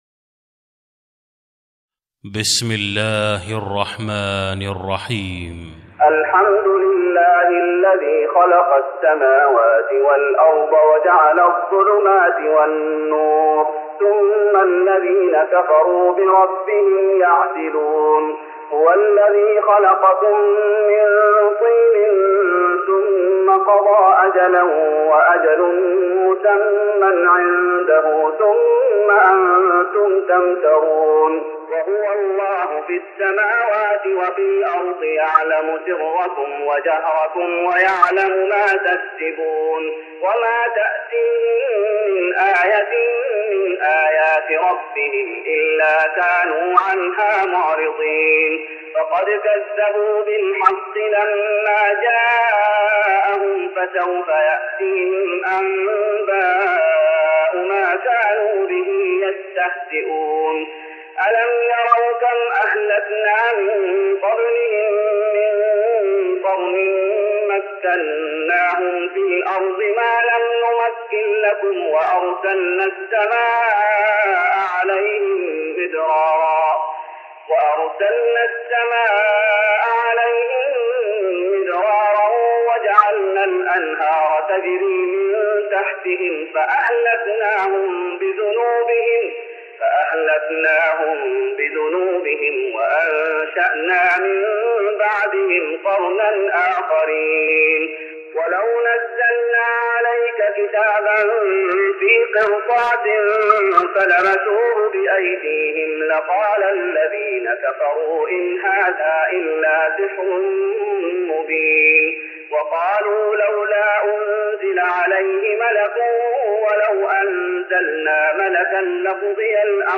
تراويح رمضان 1414هـ من سورة الأنعام (1-72) Taraweeh Ramadan 1414H from Surah Al-An’aam > تراويح الشيخ محمد أيوب بالنبوي 1414 🕌 > التراويح - تلاوات الحرمين